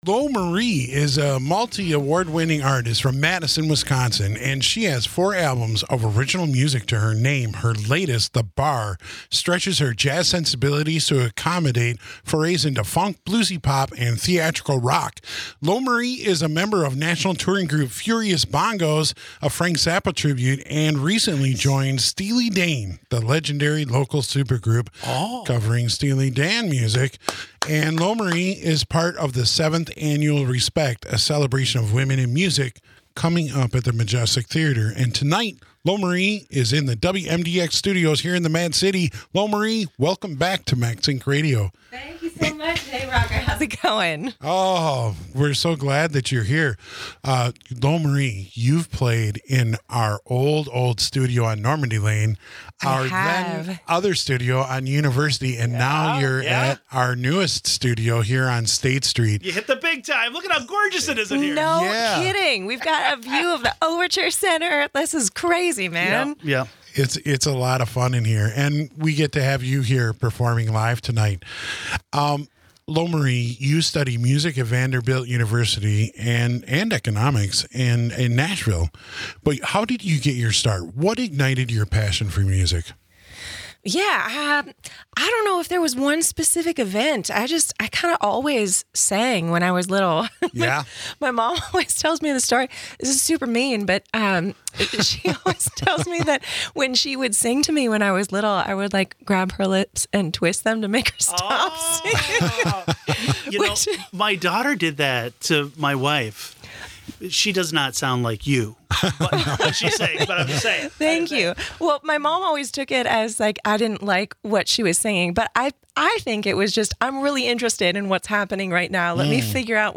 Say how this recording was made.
performing in the Lair at the WMDX studio on State Street in Madison